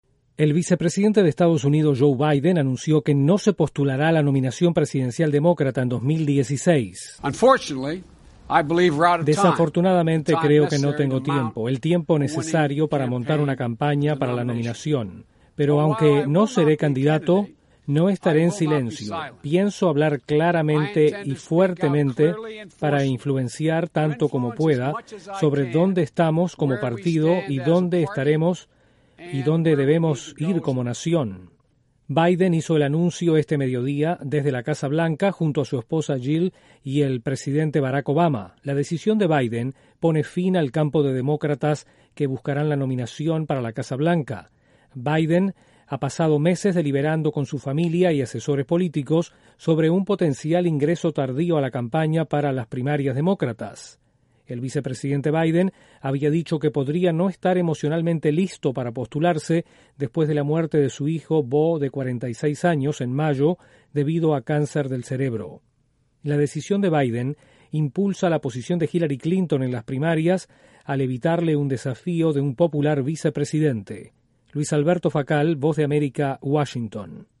El vicepresidente de EE.UU. Joe Biden anuncia que no se postulará para la nominación presidencial demócrata. Desde la Voz de América en Washington informa